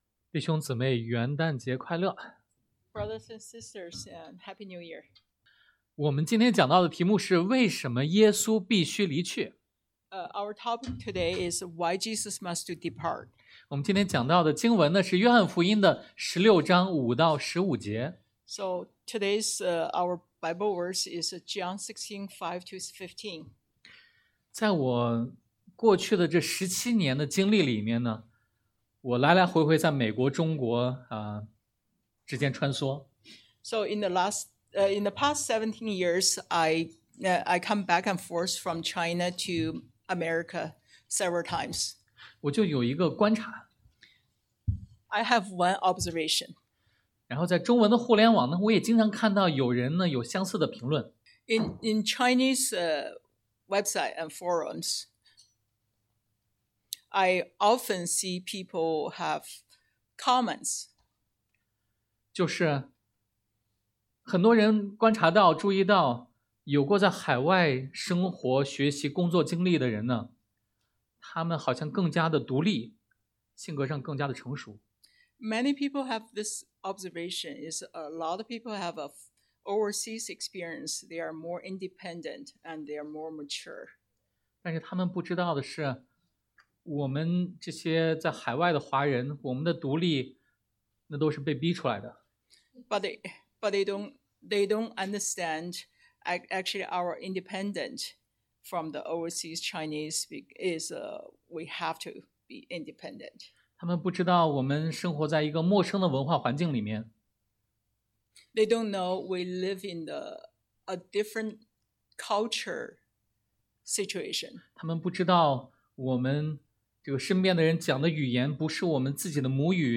Passage: 約翰福音 John 16: 5-15 Service Type: Sunday AM